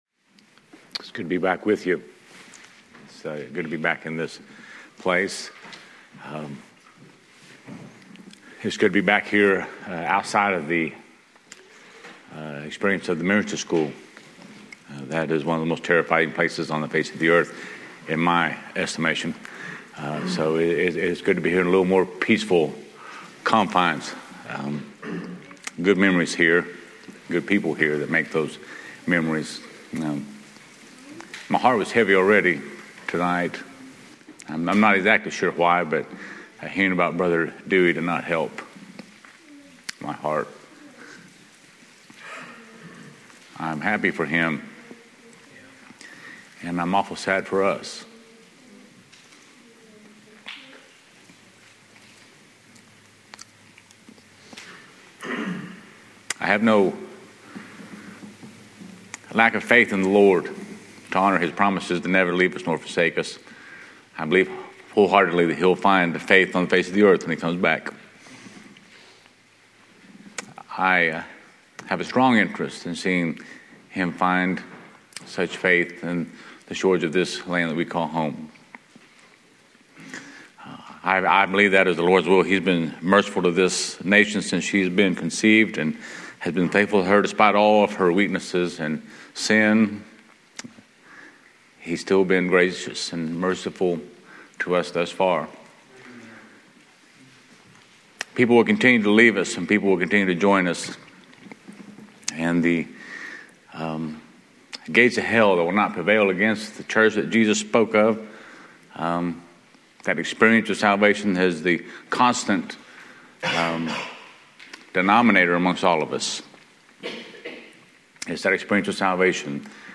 A message from the series "Sunday Morning Messages."